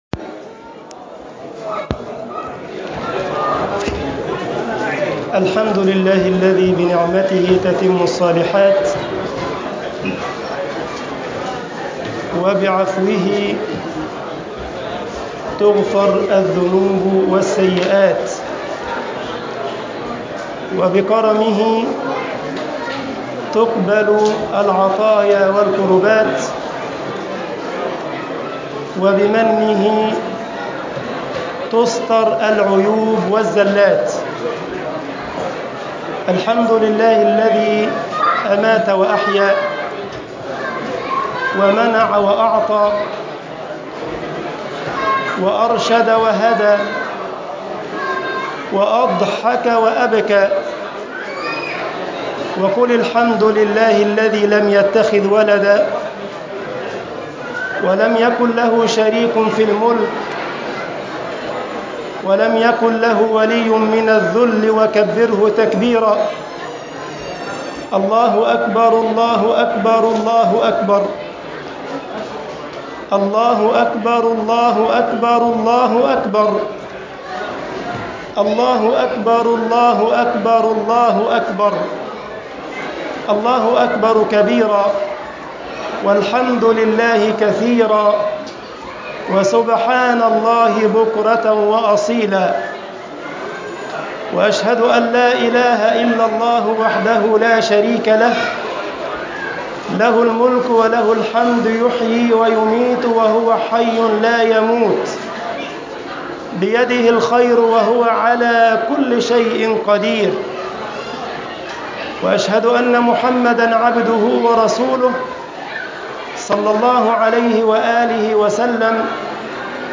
خطبة عيد الفطر